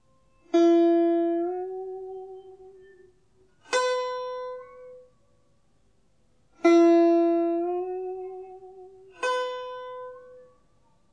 Đàn tranh - Bắc Mê-Hà Giang tình yêu của tôi
Ngón nhấn luyến: là ngón sử dụng các ngón nhấn để luyến hai hay ba âm có độ cao khác nhau, âm thanh nghe mềm mại, uyển chuyển gần với thanh điệu tiếng nói.
Nhấn luyến lên: nghệ nhân gảy vào một dây để vang lên, tay trái nhấn dần lên dây đó làm âm thanh cao lên hoặc tiếp tục nhấn cho cao lên nữa. Ví dụ: (022-28) Upward accent with legato: